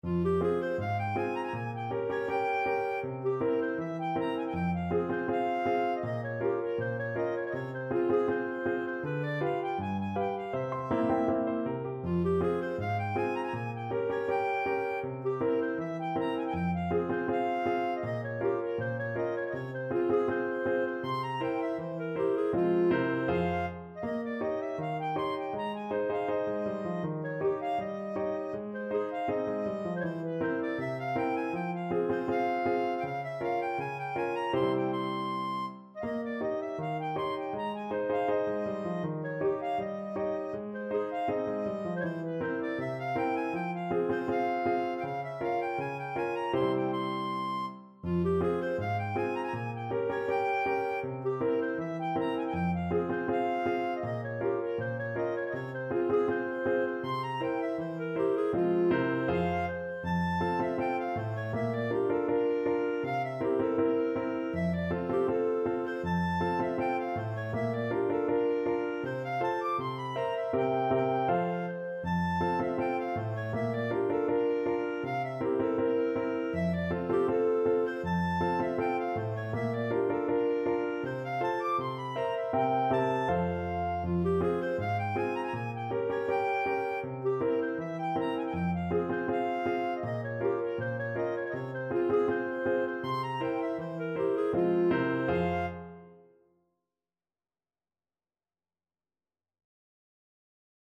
Clarinet
2/4 (View more 2/4 Music)
F minor (Sounding Pitch) G minor (Clarinet in Bb) (View more F minor Music for Clarinet )
=c.80 Andante